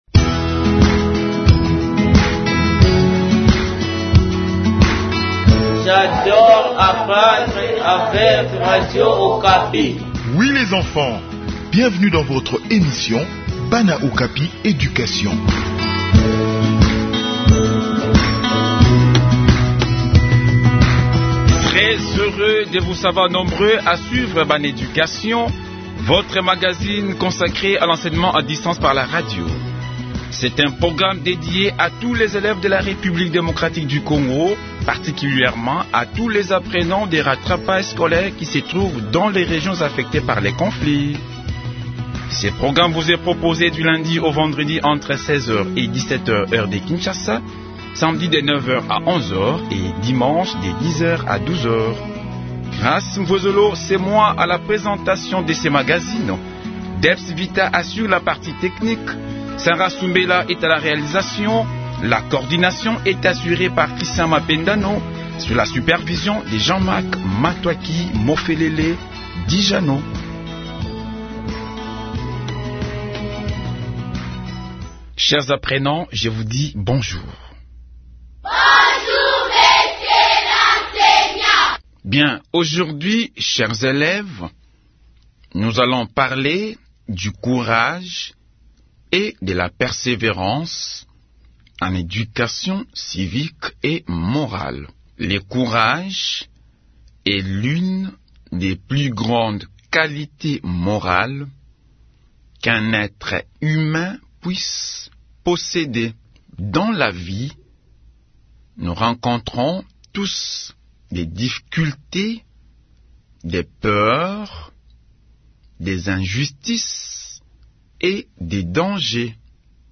Enseignement à distance : leçon sur le courage et la persévérance